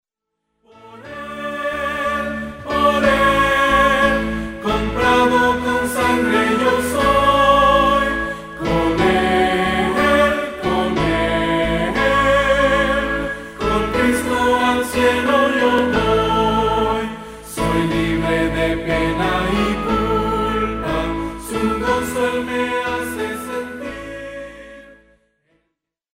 que presenta himnos tradicionales con un enfoque fresco